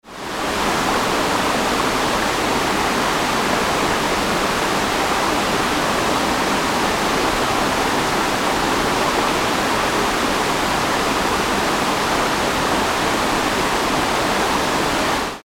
Gemafreie Sounds: Wasser - Wasserfall